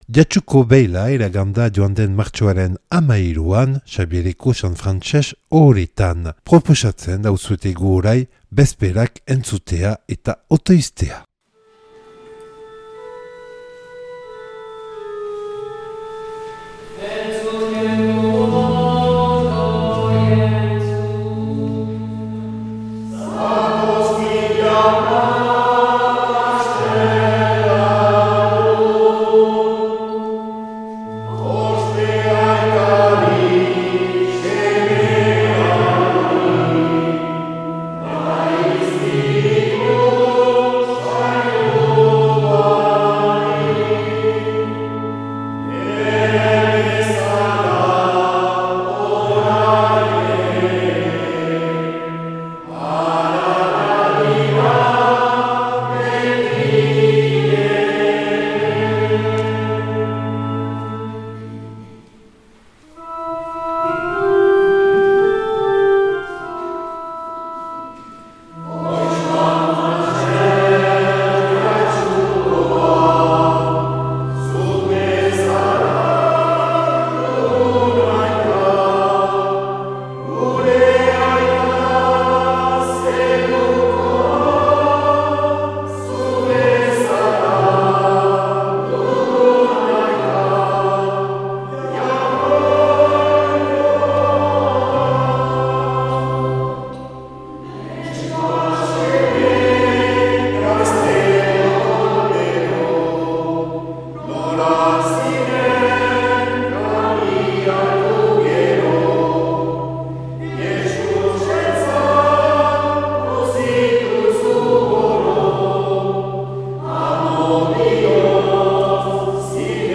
Jatsuko beilaren bezperak Xabiereko San Frantses ohoretan